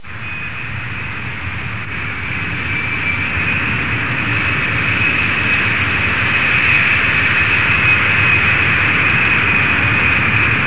دانلود آهنگ طیاره 19 از افکت صوتی حمل و نقل
دانلود صدای طیاره 19 از ساعد نیوز با لینک مستقیم و کیفیت بالا
جلوه های صوتی